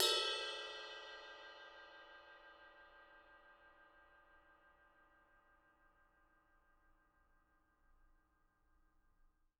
R_B Ride Bell 01 - Room.wav